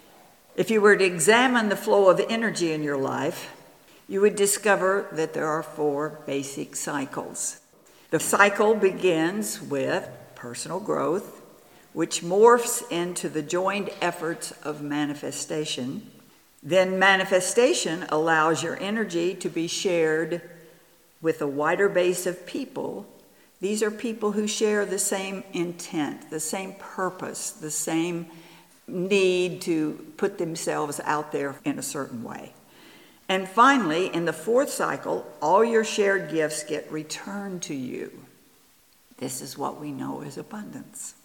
This incredible recording of a 1/2 day workshop will be your guide on that journey.
Workshop Recordings